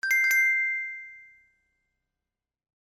/ F｜演出・アニメ・心理 / F-03 ｜ワンポイント1_エフェクティブ
感情：ハッピー 04キンキン